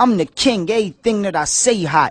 Hip-Hop Vocals Samples